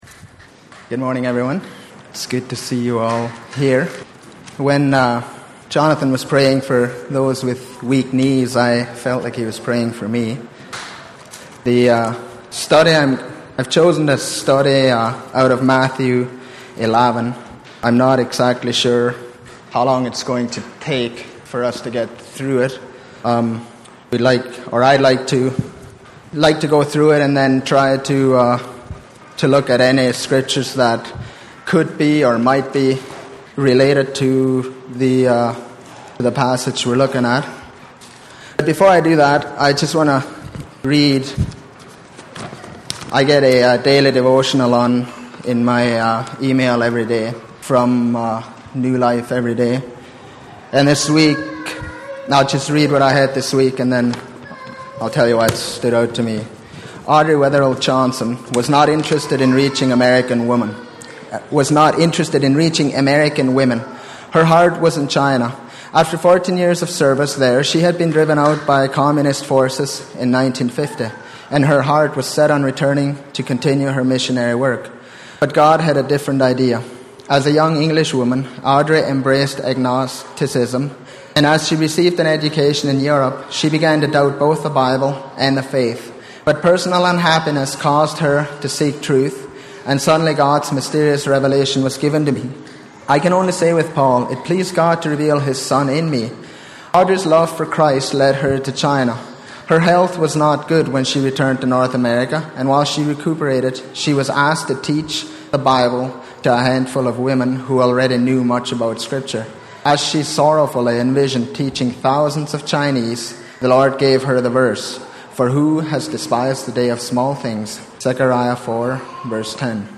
Matthew 11:1-6 Service Type: Sunday Morning %todo_render% « Remember Where you Came From Friend or Foe?